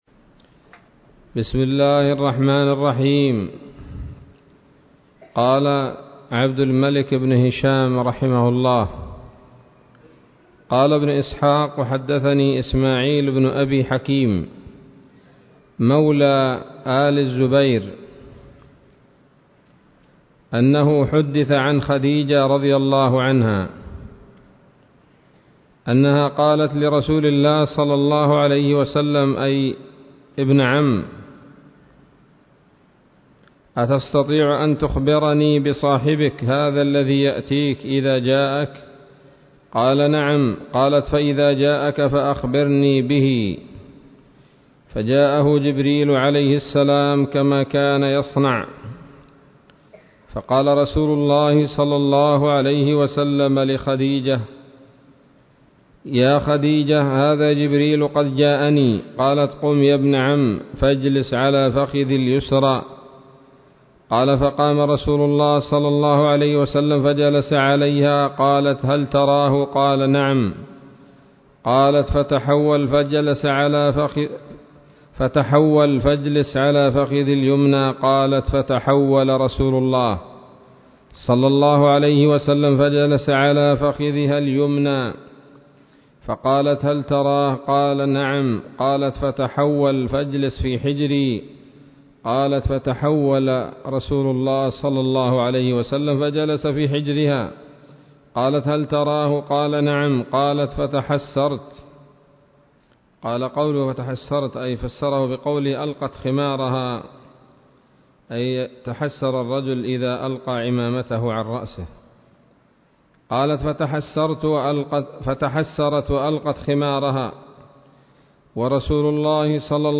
الدرس الثالث والعشرون من التعليق على كتاب السيرة النبوية لابن هشام